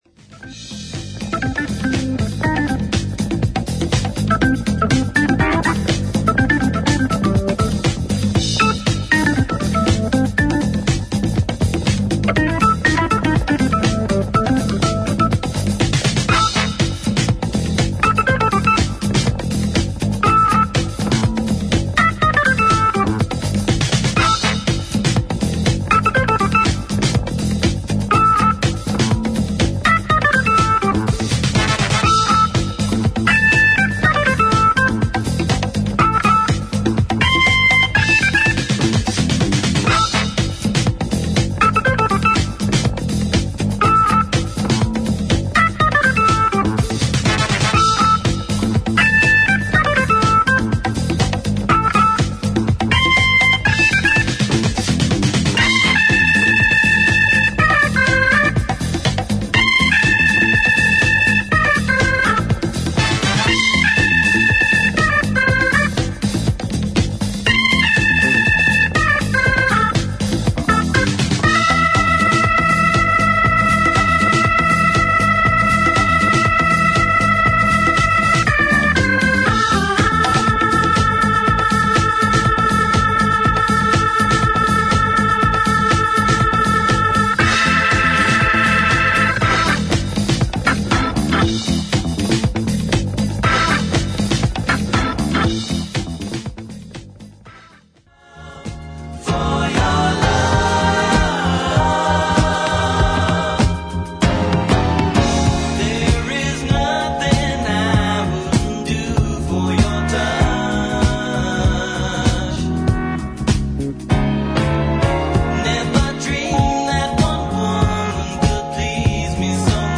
AOR/ブルー・アイド・ソウル的な雰囲気を持った名曲